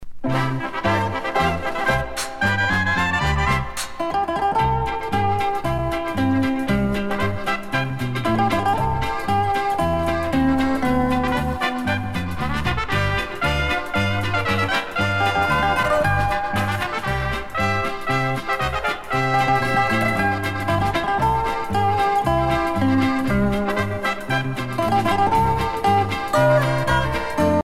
danse : marche
Pièce musicale éditée